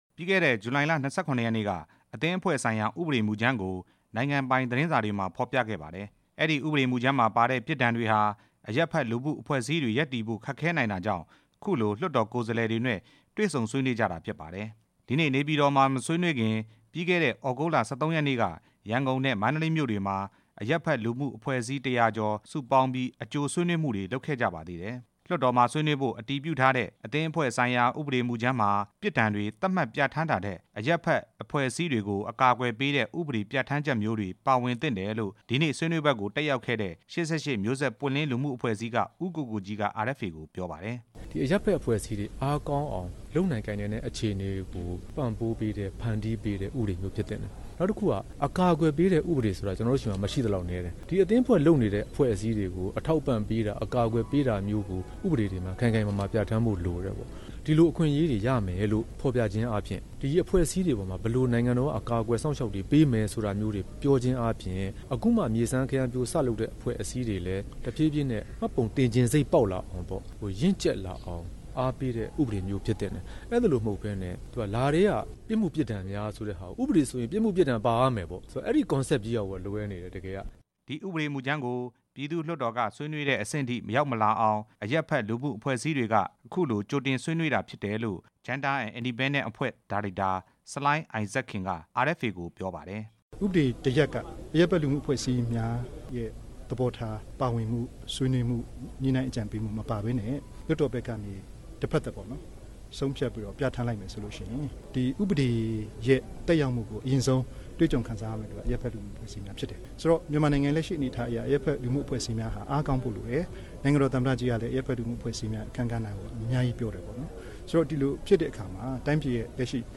ဆွေးနွေးပွဲ တင်ပြချက်